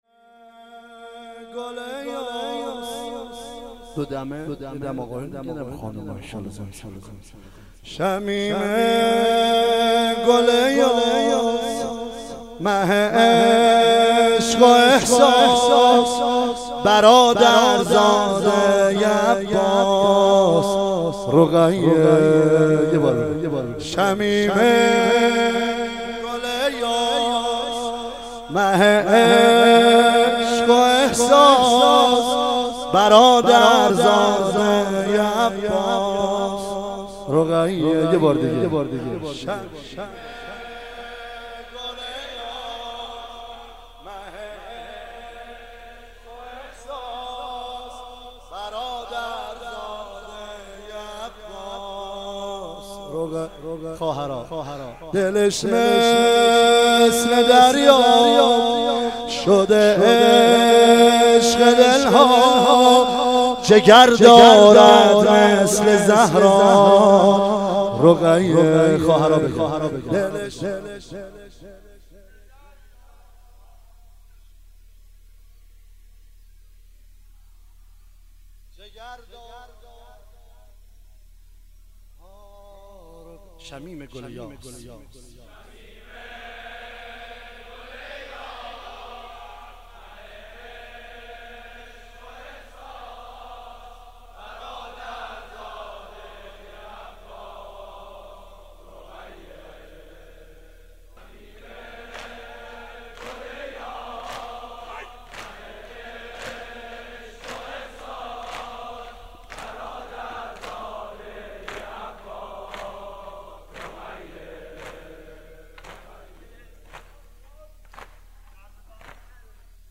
دودمه